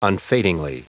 Prononciation du mot unfadingly en anglais (fichier audio)
Prononciation du mot : unfadingly